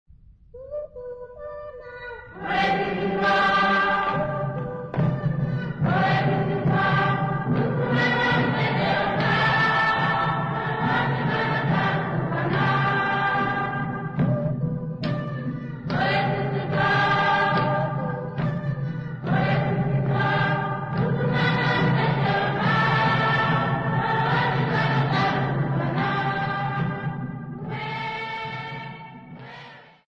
Tondoro church music workshop participants
Sacred music Namibia
Choral music Namibia
Ngoma (Drum) Namibia
field recordings
Practise of new song at Tondoro church music workshop with drum and clapping accompaniment.